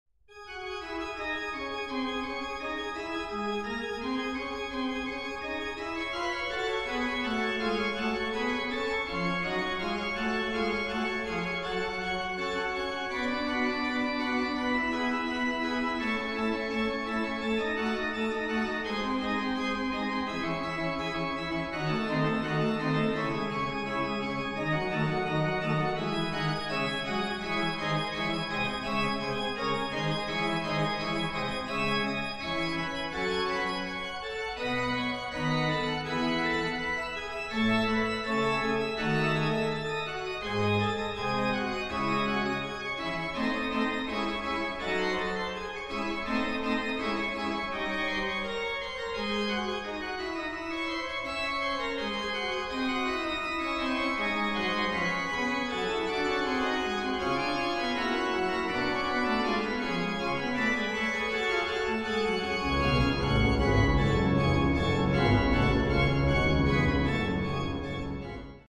• Качество: 128, Stereo
громкие
без слов
инструментальные